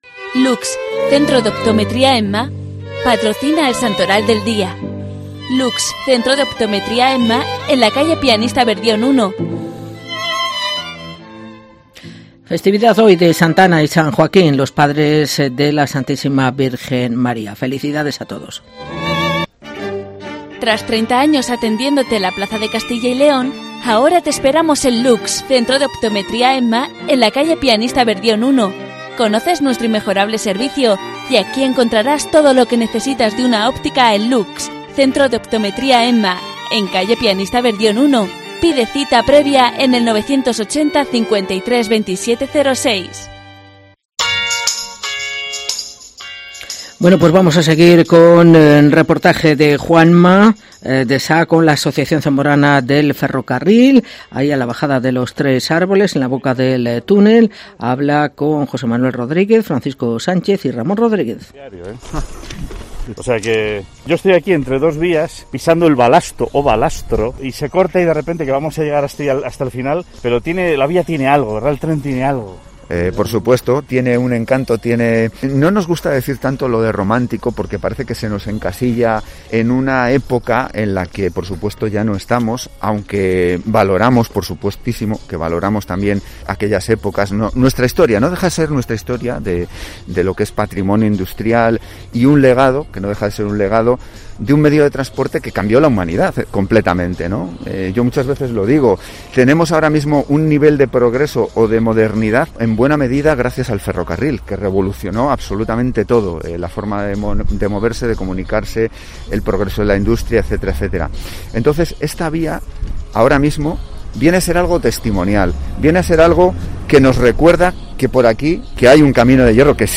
AUDIO: Reportaje de la Asociación Zamorana del Ferrocarril